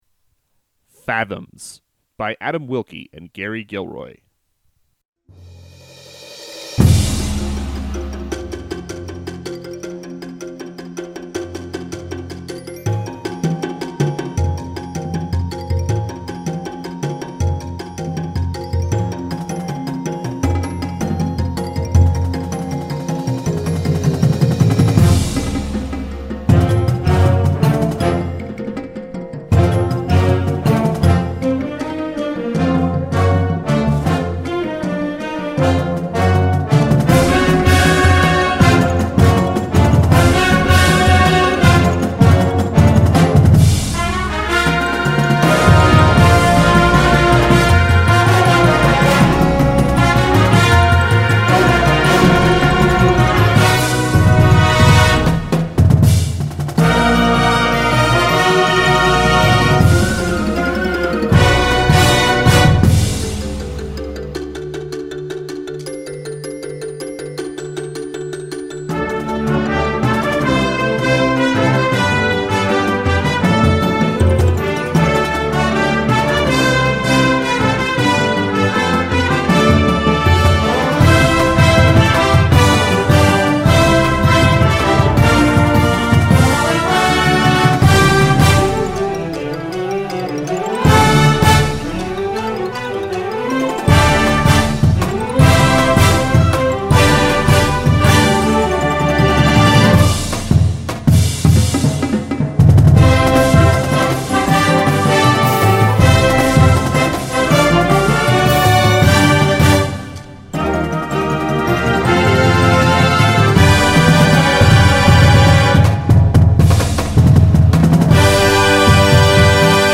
a nautical themed Marching Band Show